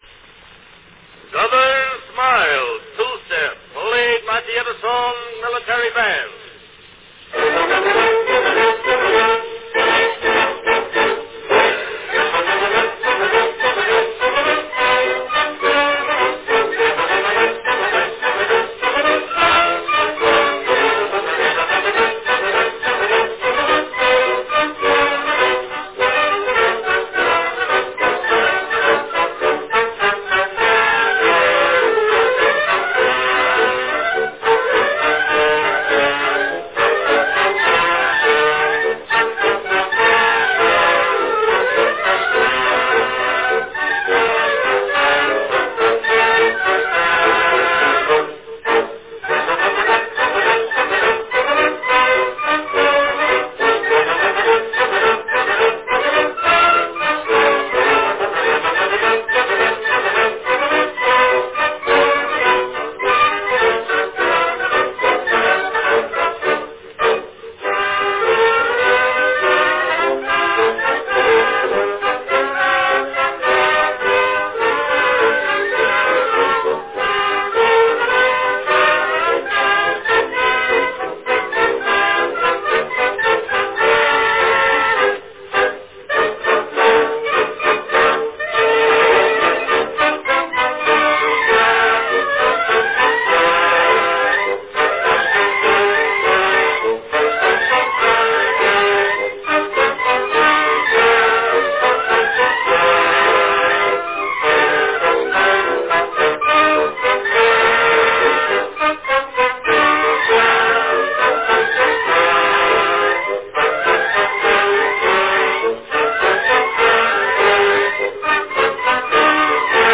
Category Band